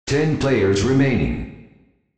TenPlayersRemaining.wav